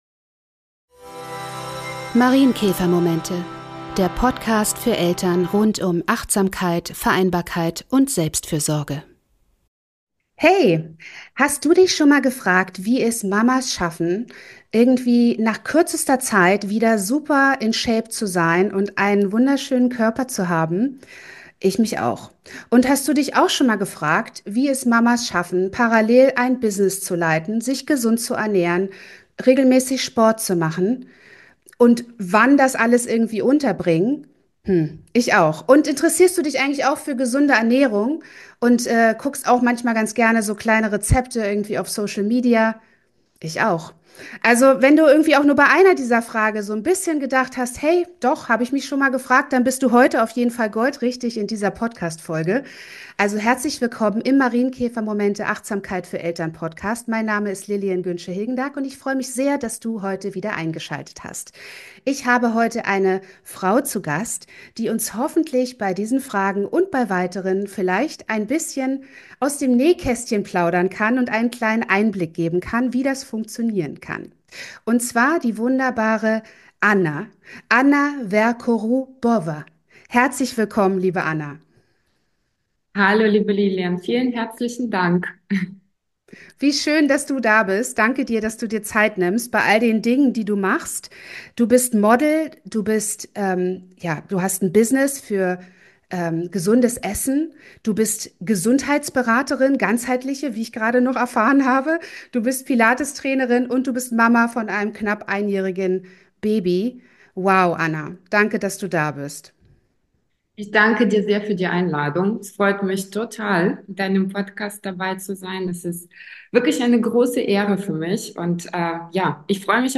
MARIENKÄFERMOMENTE no. 29: Zwischen Business, Baby & bewusster Selbstfürsorge - im Talk